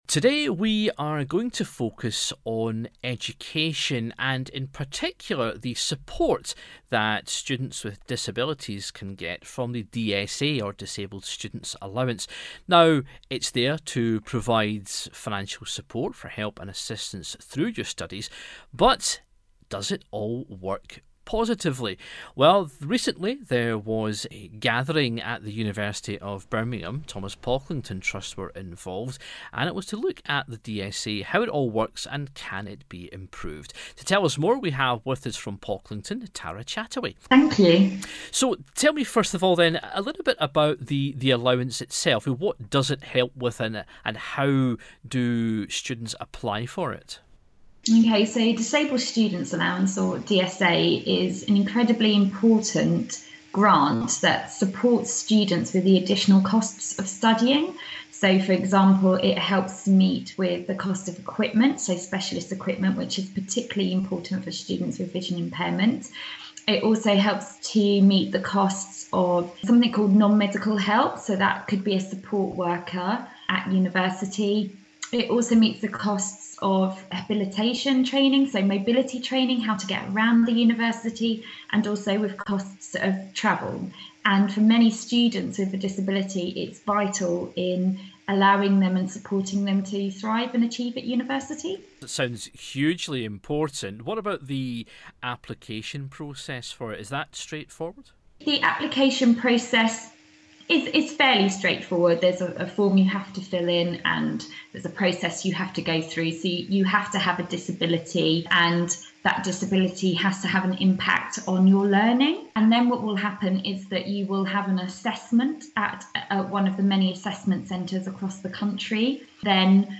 a visually impaired university student